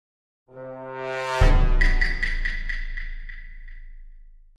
Among Us Role Reveal Start Round Sound Effect Free Download